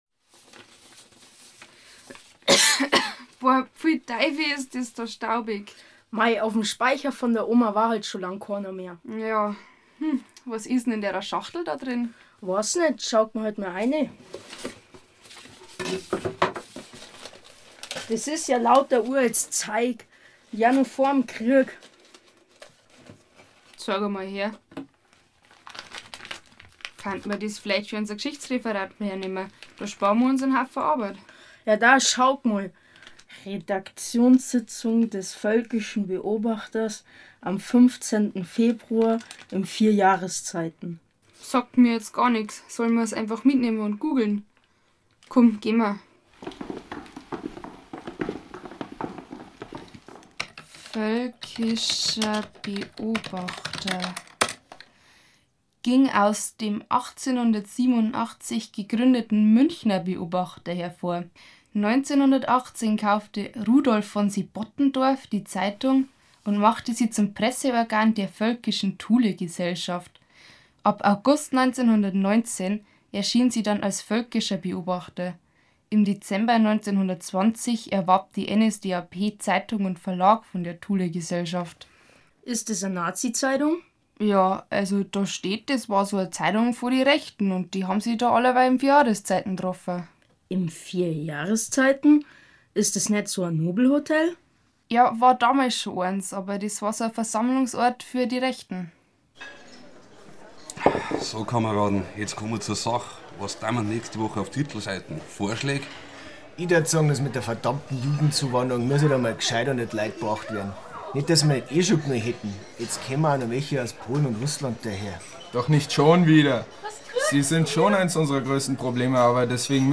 Sechs Schülergruppen produzierten Audioguides und konnten dabei